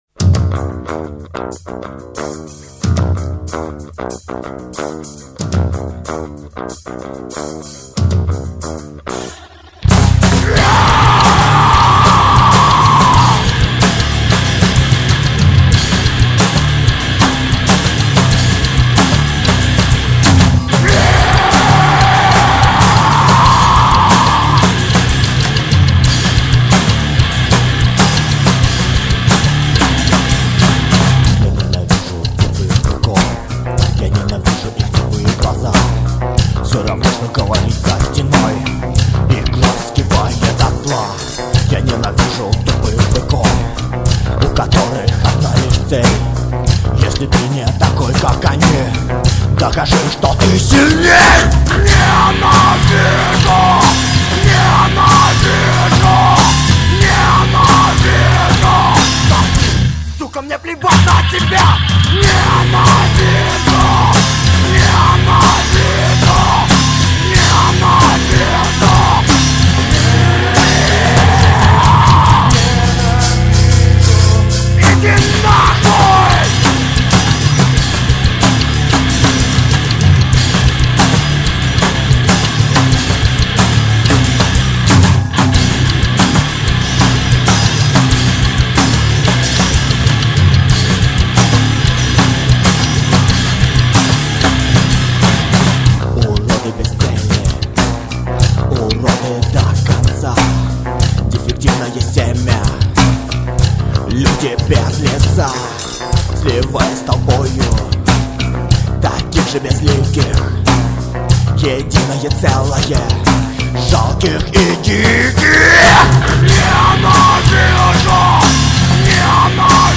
вокал
бас гитара
ударные